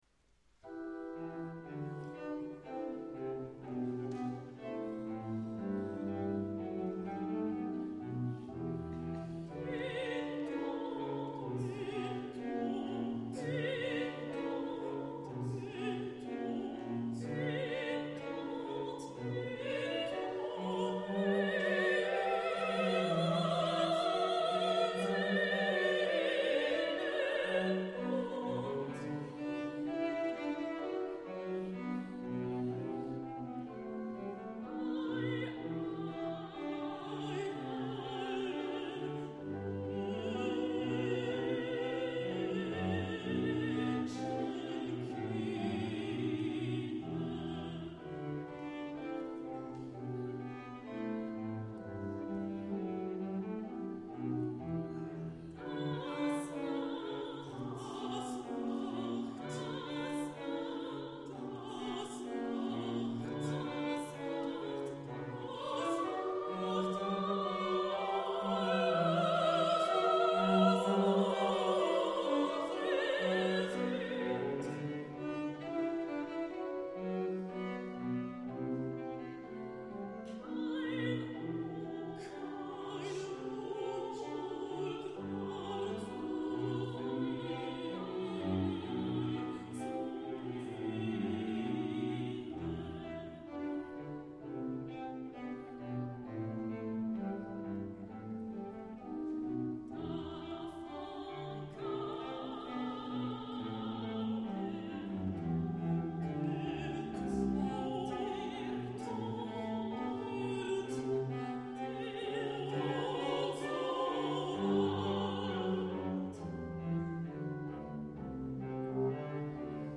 donnée à Porrentruy le 23 juin 2012
Verset 2 duo
soprano
alto